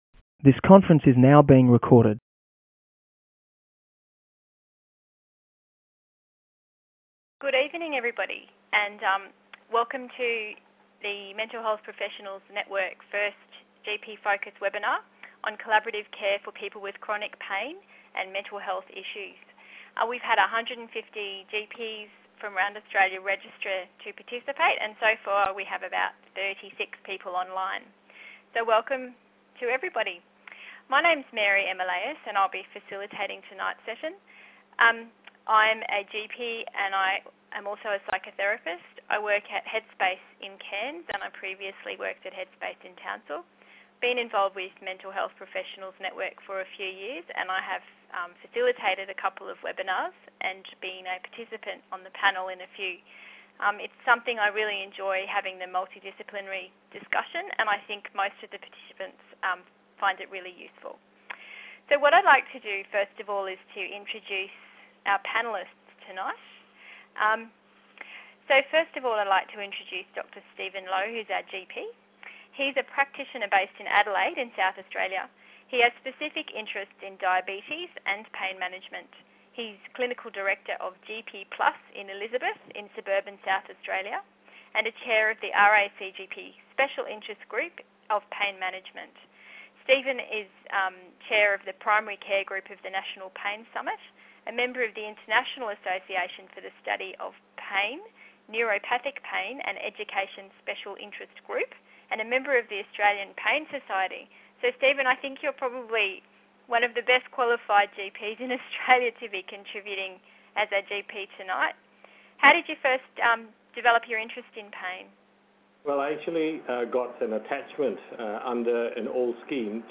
Chronic pain and mental health: An interdisciplinary case study panel discussion for general practitioners - MHPN